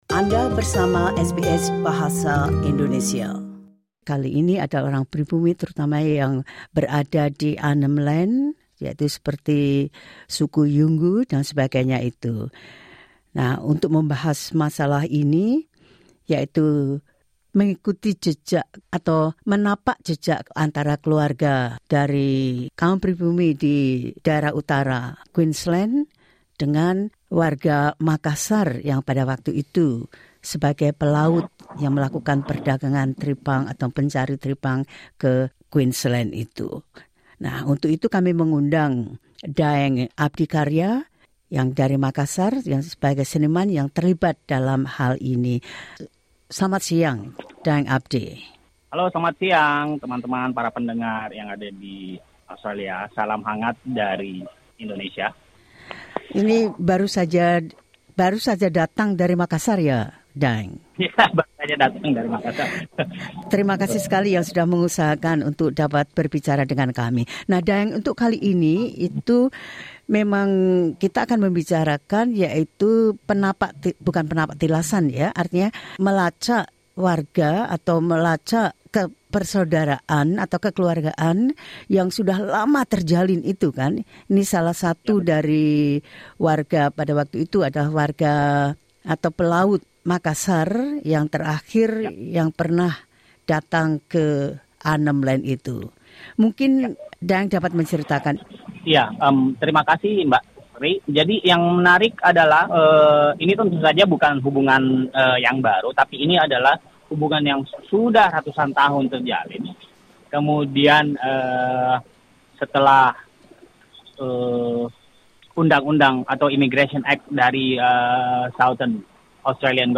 Dalam wawancara ini